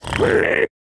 25w18a / assets / minecraft / sounds / mob / piglin / retreat1.ogg
retreat1.ogg